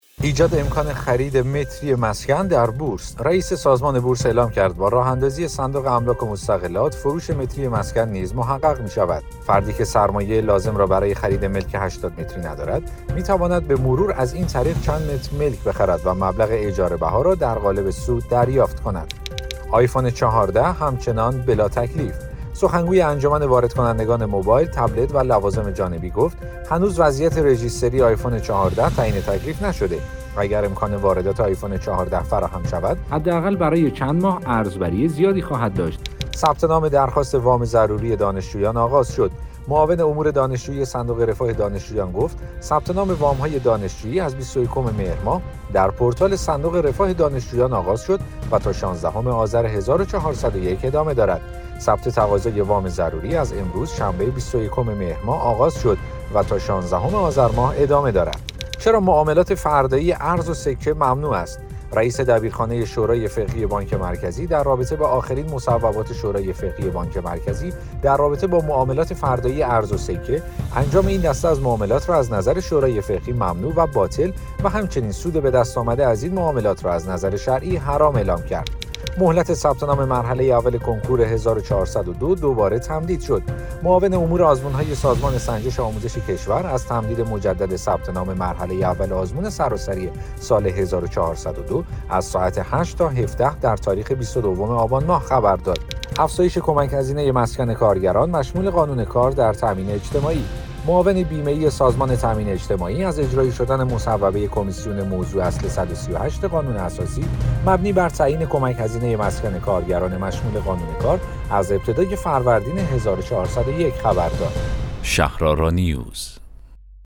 اخبار صوتی - شنبه شب ۲۱ آبان ۱۴۰۱